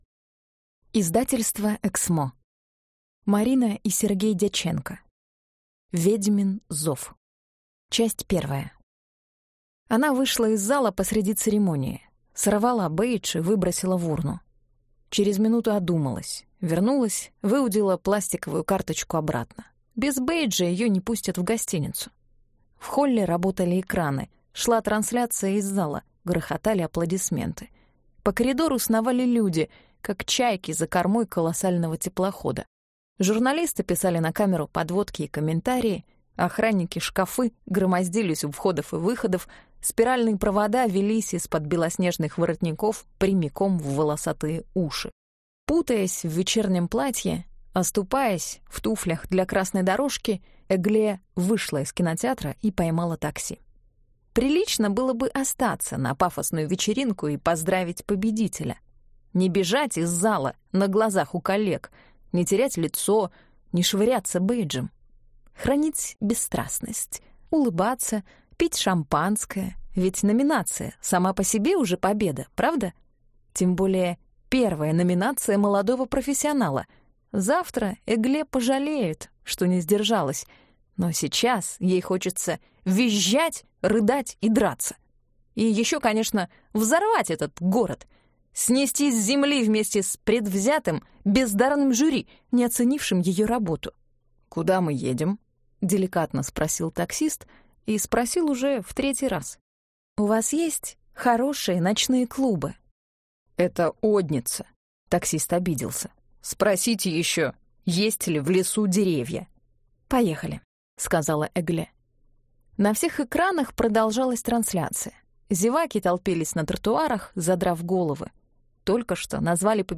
Аудиокнига Ведьмин зов | Библиотека аудиокниг